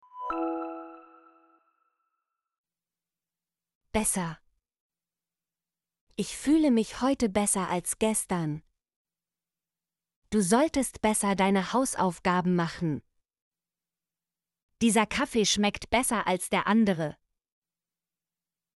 besser - Example Sentences & Pronunciation, German Frequency List